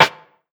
RIM 07    -L.wav